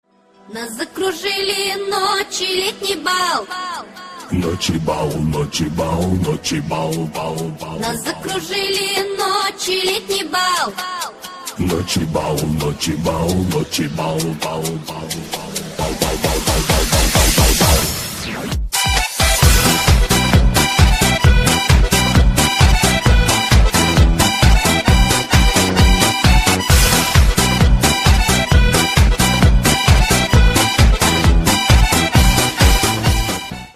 веселые
поп